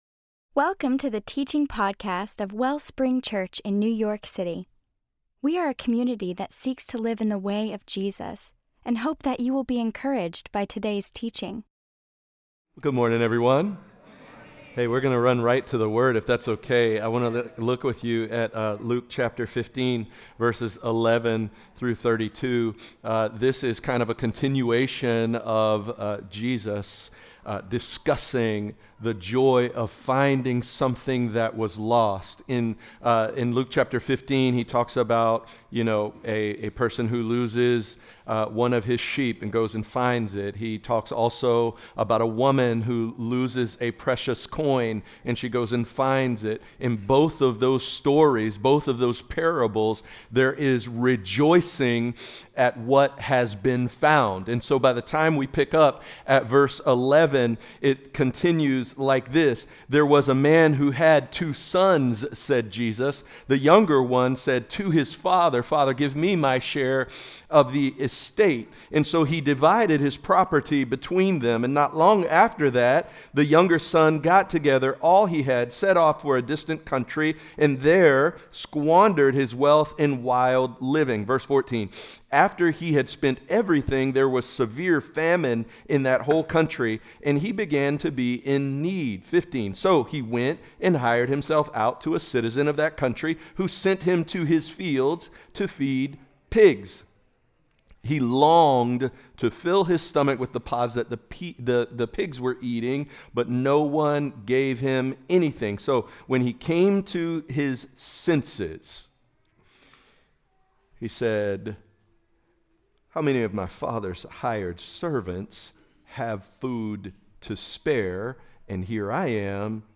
DRAW NEAR - THE LOST SON (GUEST SPEAKER